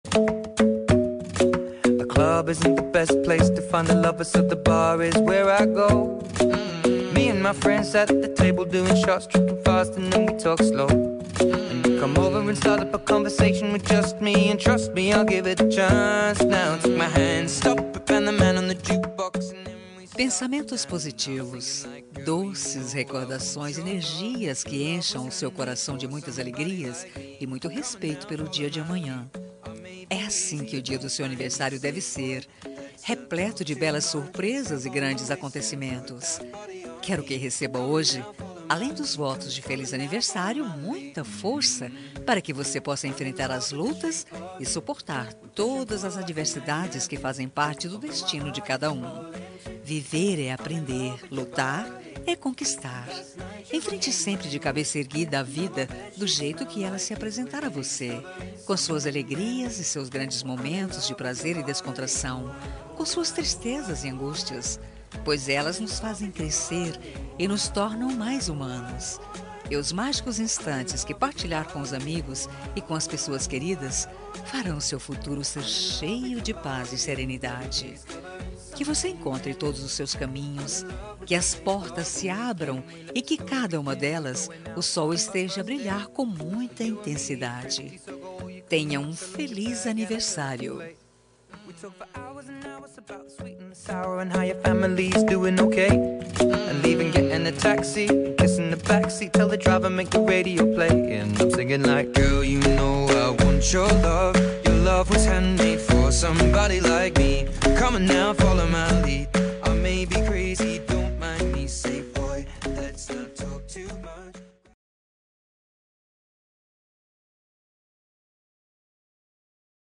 Aniversário de Ficante – Voz Feminina – Cód: 8875